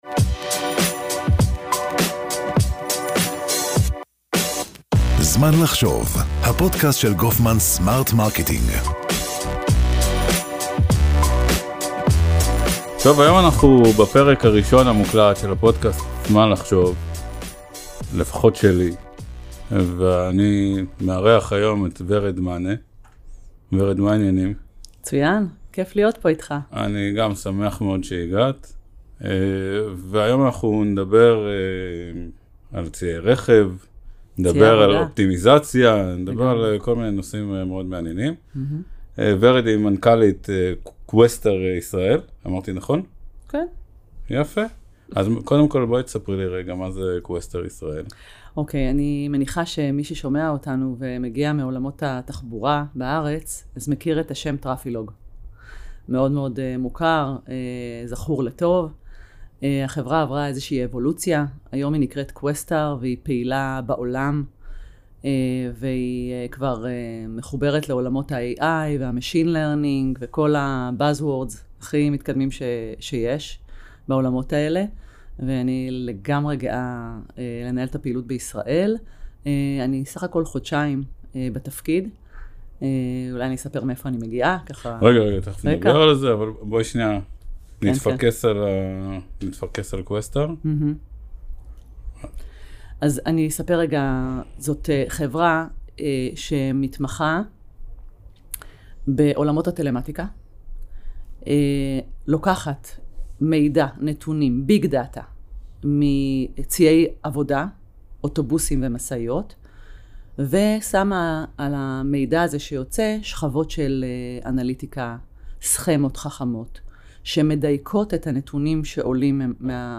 לשיחה מרתקת על איך בינה מלאכותית וטלמטיקה משנים את פני ניהול ציי רכב.